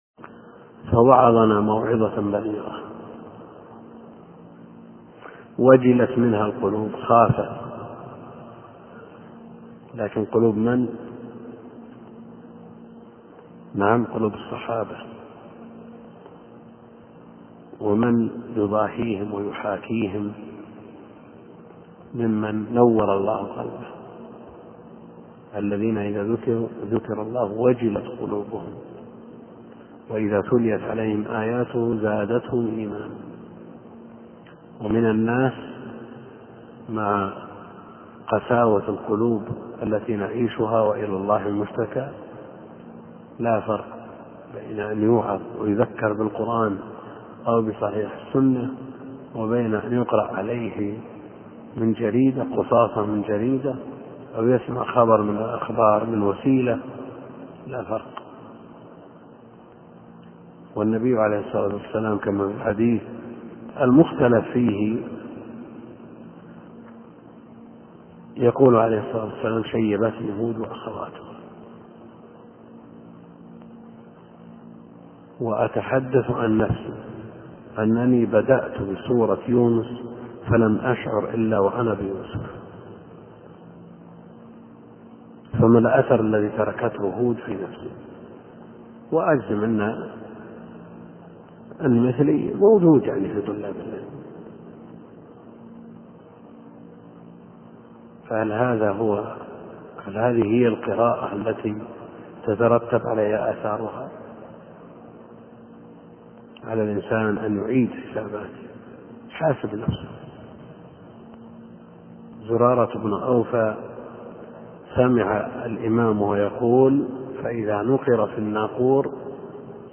موعظة بليغة وجِلت منها القلوب